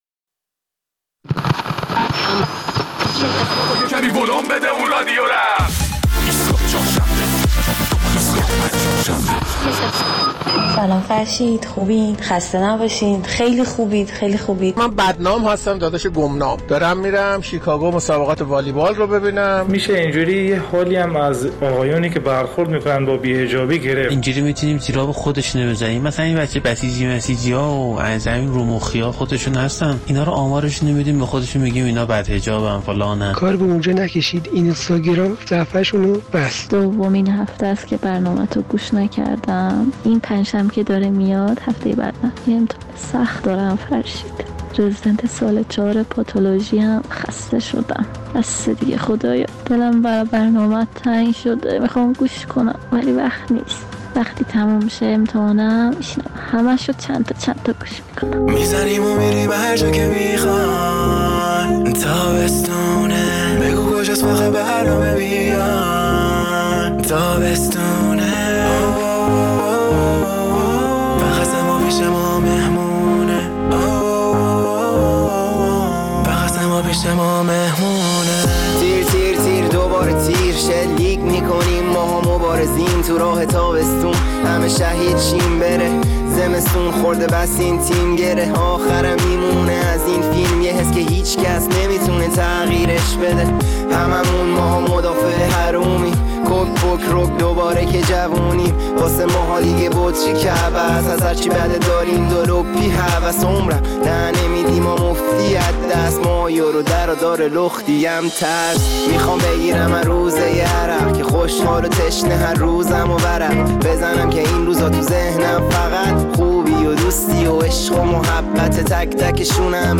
در این برنامه نظر مخاطبان ایستگاه فردا را در مورد درخواست دادسرای ارشاد از مردم برای گزارش دادن موارد منکراتی‌ای که مشاهده می‌کنند، می‌شنویم.